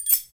Index of /90_sSampleCDs/Roland L-CD701/PRC_Asian 2/PRC_Windchimes
PRC CHIME05R.wav